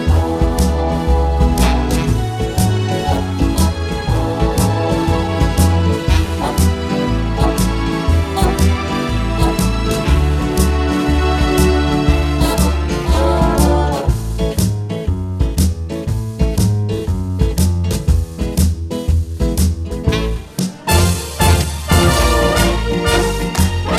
Two Semitones Down Jazz / Swing 2:30 Buy £1.50